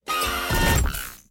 cannonEnter.ogg